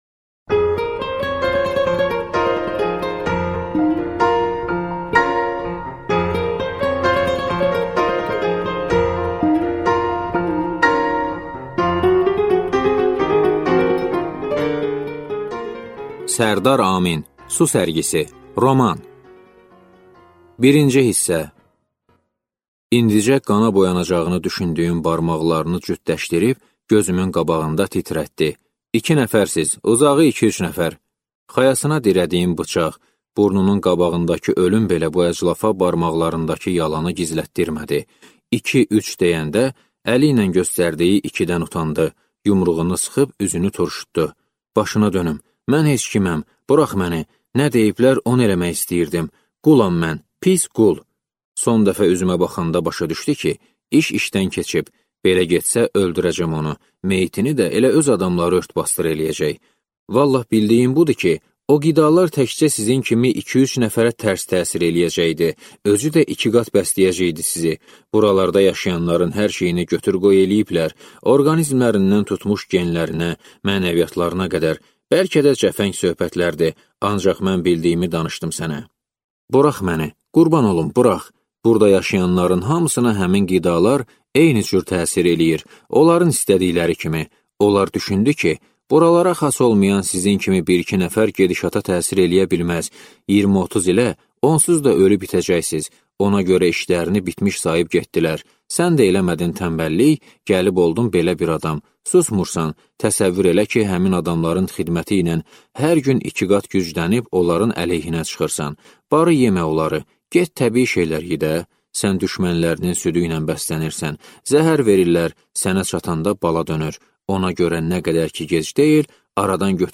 Аудиокнига Su sərgisi | Библиотека аудиокниг